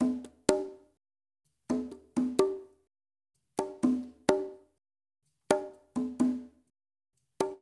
LAY CONGAS-R.wav